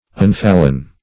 unfallen - definition of unfallen - synonyms, pronunciation, spelling from Free Dictionary